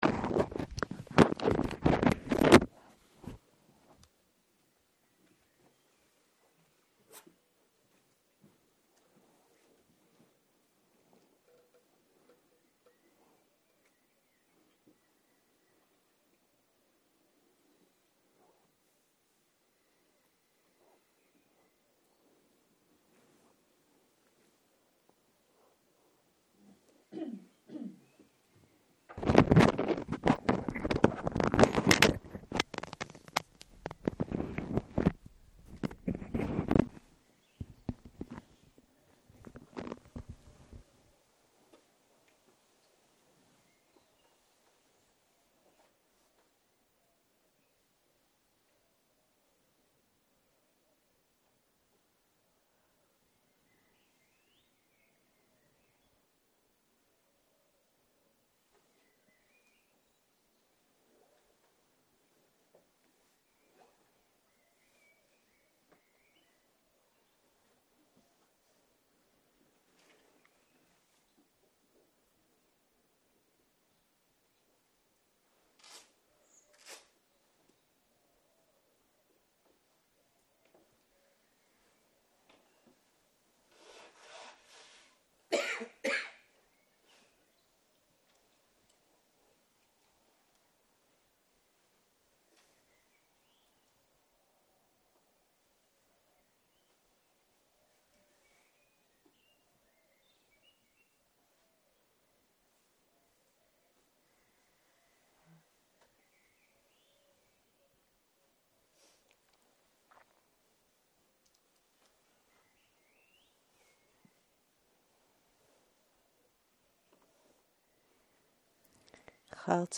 04.03.2023 - יום 2 - צהרים - מדיטציה מונחית - תשומת לב לרווחה בגוף והנשימה - הקלטה 3